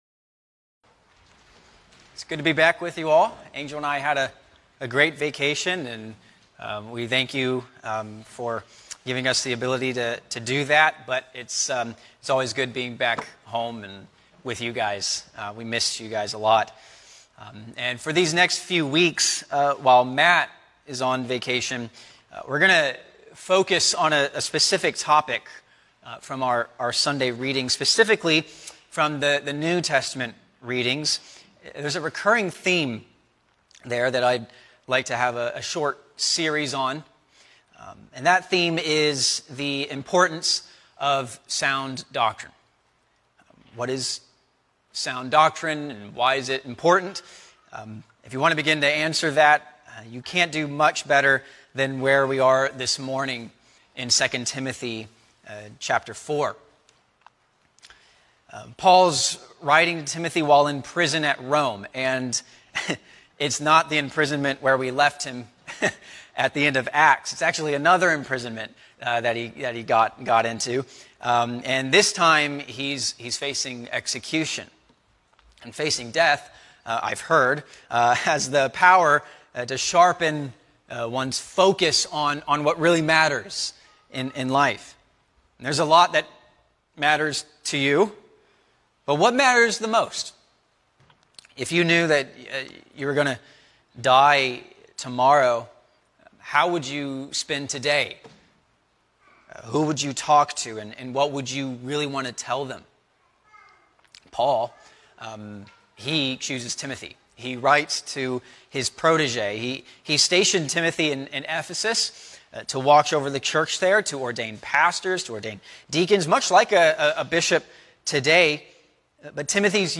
A sermon on II Timothy 4:1-8